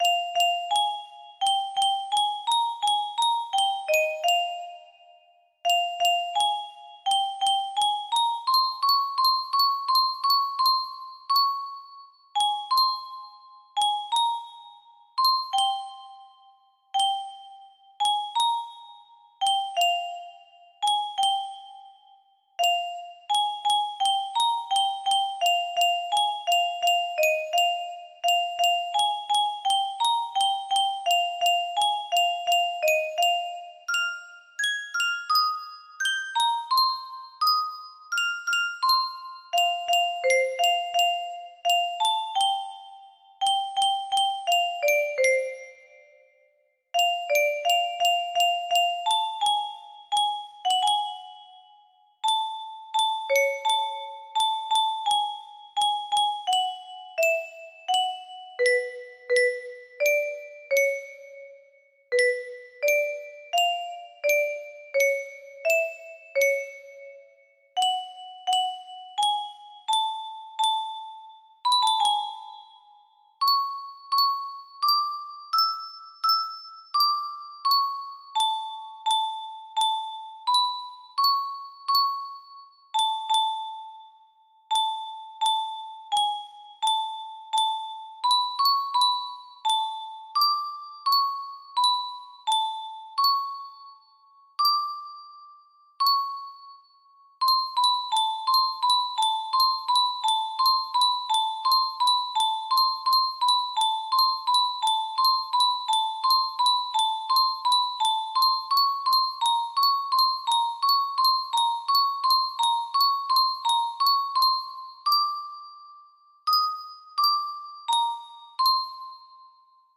Full range 60
A medley